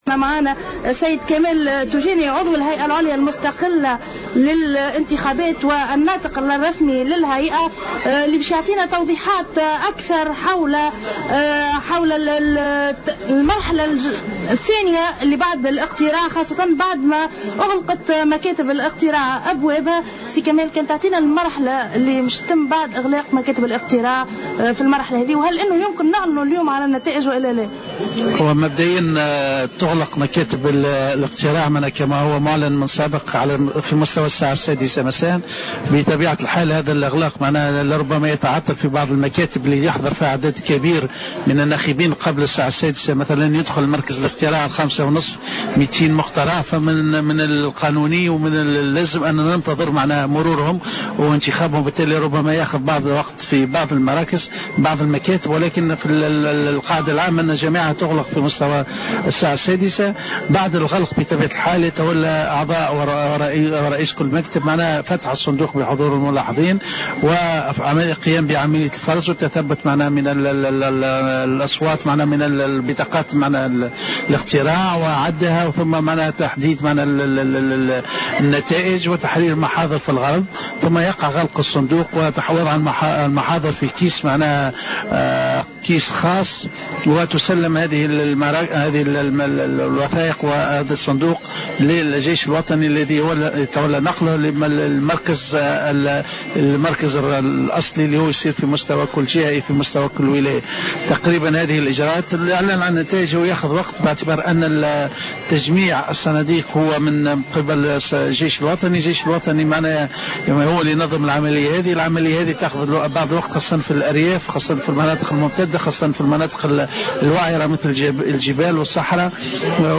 au micro de Jawhara FM